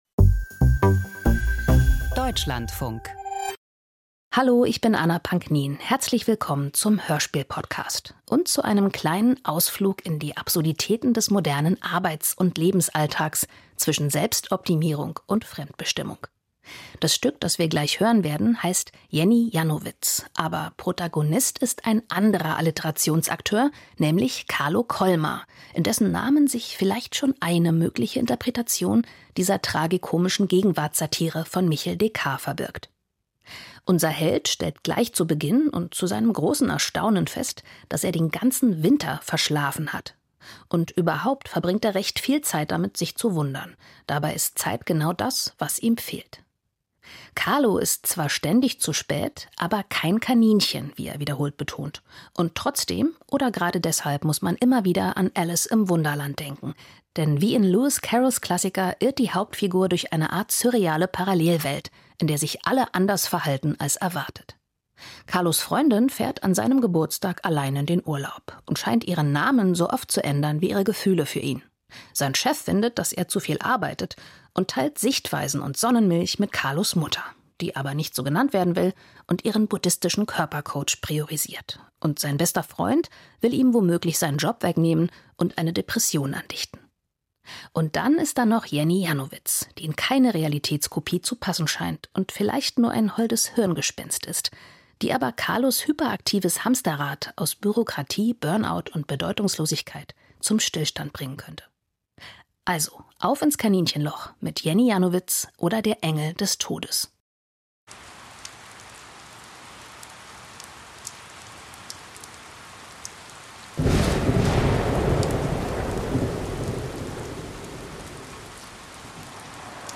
Hörspiel: Überforderung in der globalisierten Welt - Jenny Jannowitz